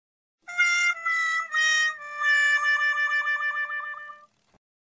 whaawhaa.mp3